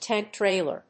アクセントtánk tràiler